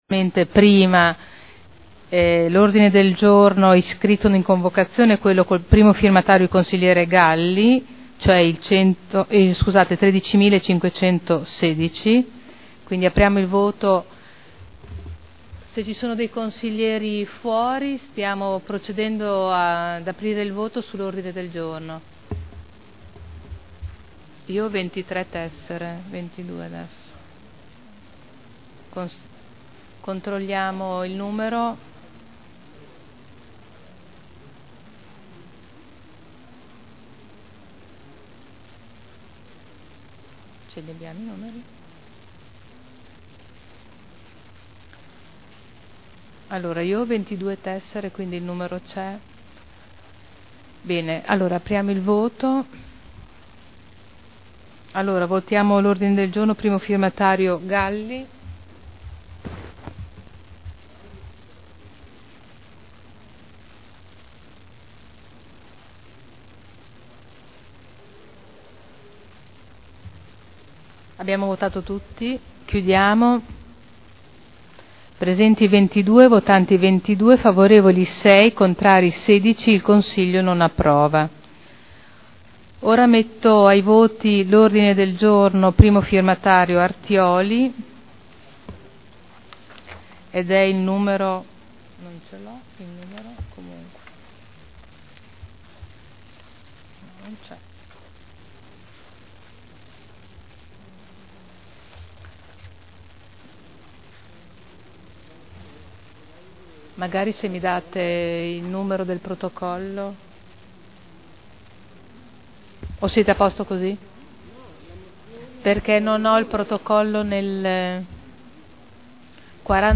Seduta del 12/04/2012.